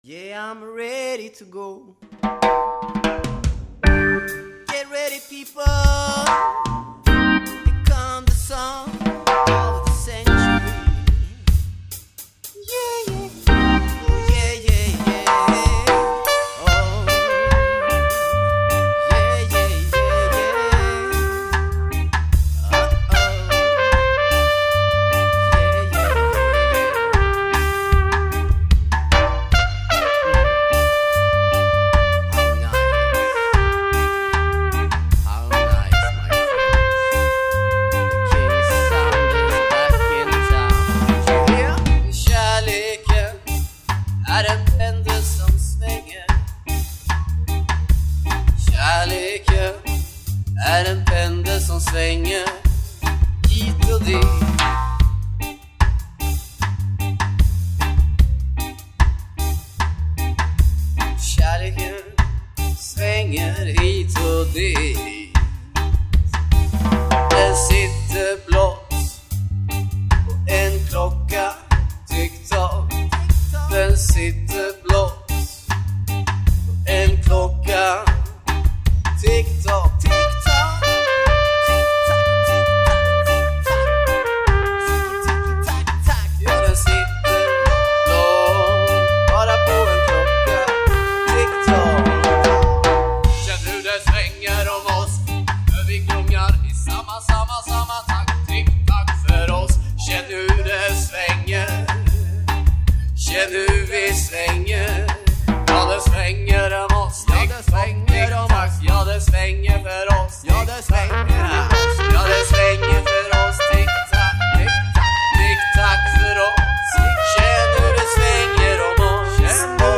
Recorded on the north-side OF TOWN